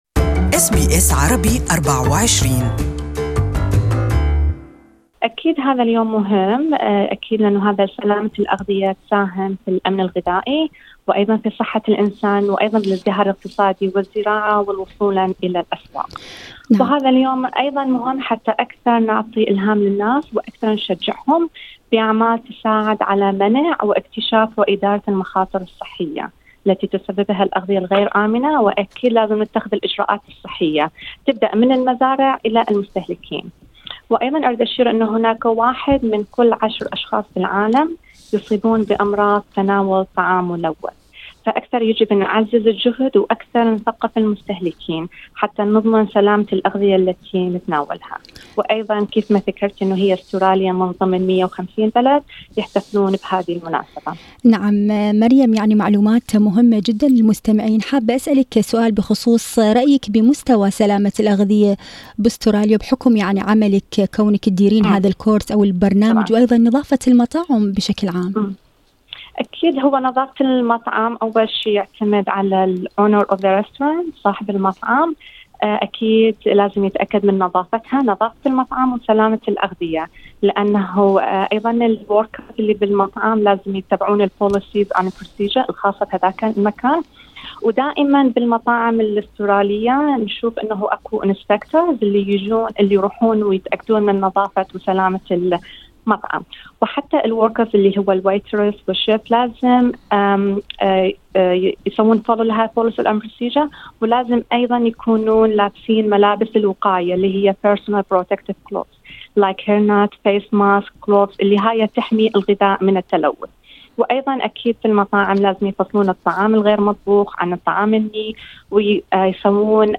المزيد عن هذا الموضوع في لقاءِ مباشر